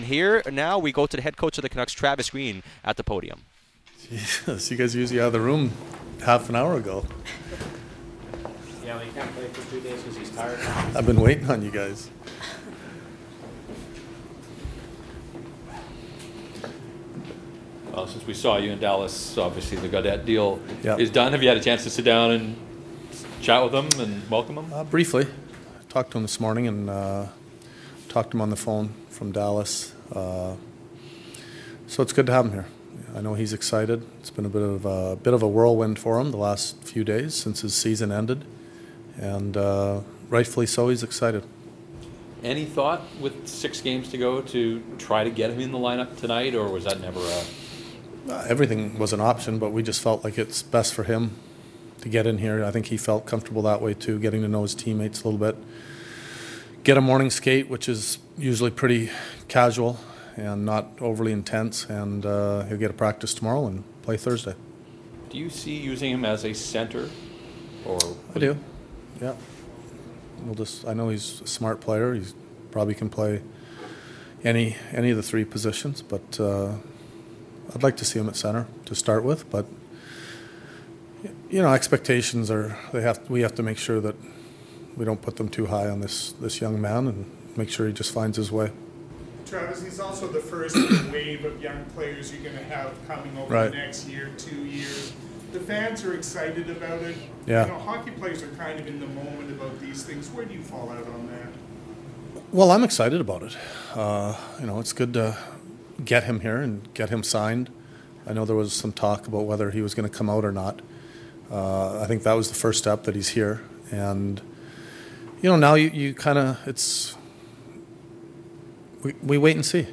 from the podium: Let Gaudette be himself and play his game